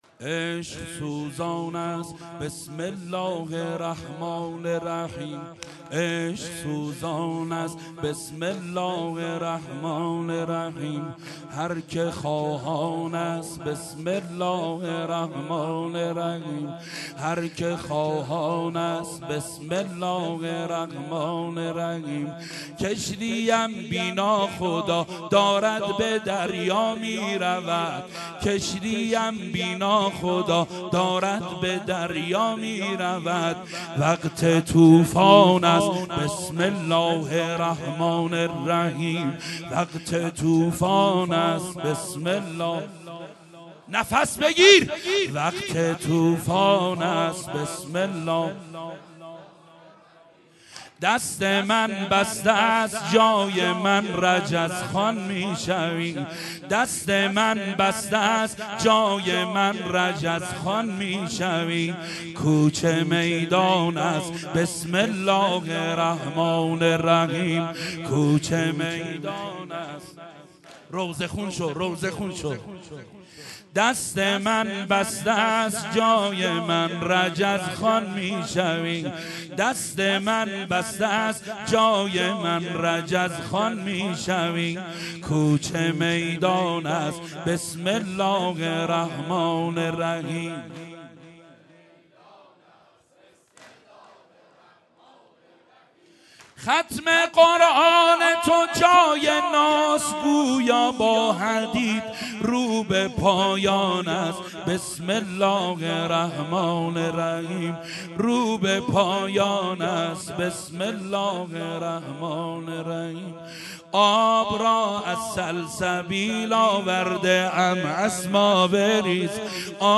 شب دوم فاطمیه دوم ۱۳۹۳
مداحی
سینه زنی قسمت دوم